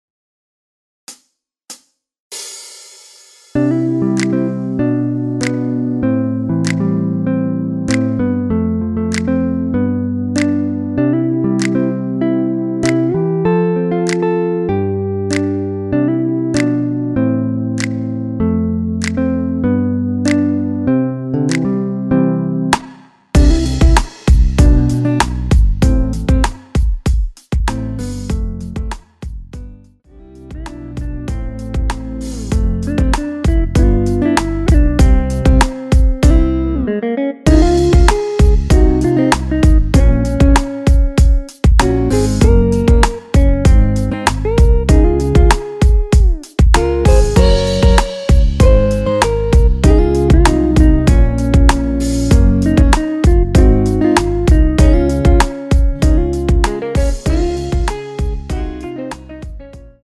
MR입니다. 전주 없이 시작하는 곡이라 시작 카운트 넣어 놓았습니다.